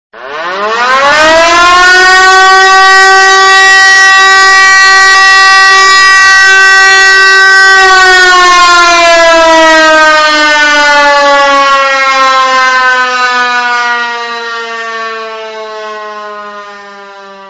Fire Alarm Sound 46990
• Category: Fire alarm